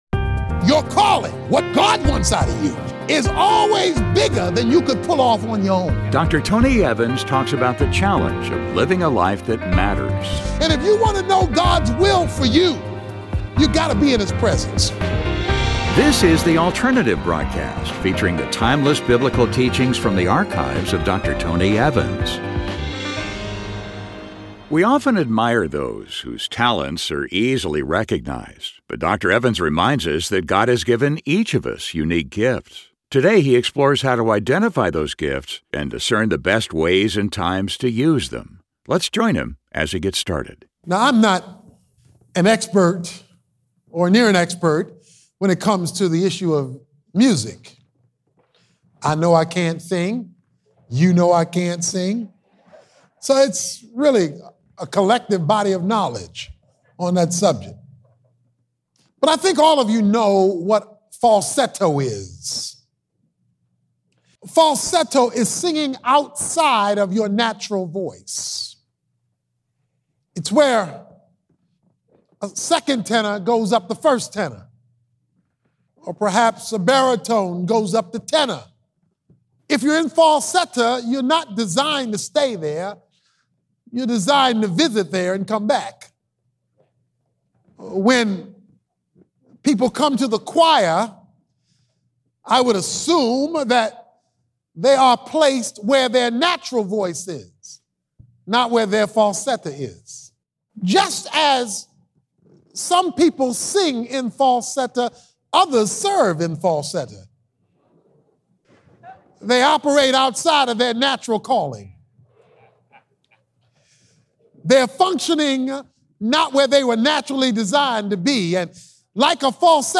We often admire those whose talents are easily recognized, but in this message, Dr. Tony Evans reminds us that God has given Â each Â of us unique gifts.